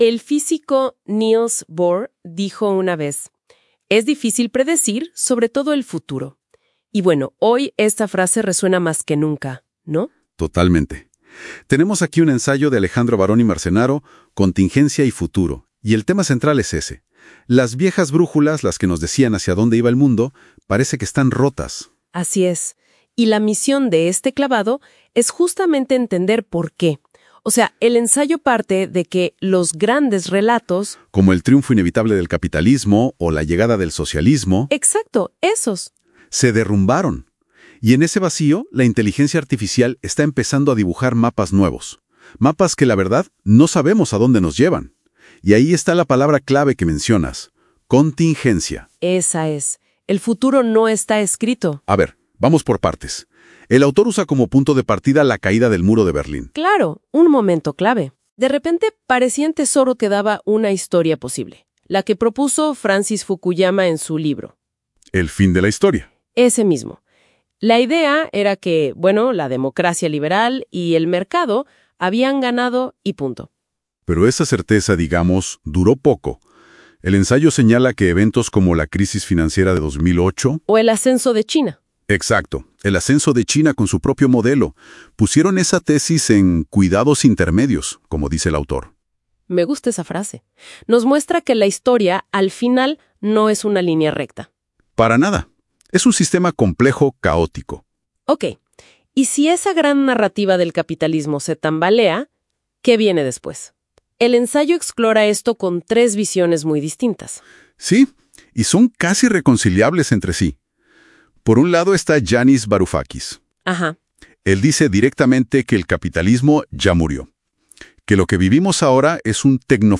Al hacer un resumen, éste produjo un diálogo entre dos personas, a quienes la edición apunta como A y M, aleatoriamente.